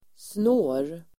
Uttal: [snå:r]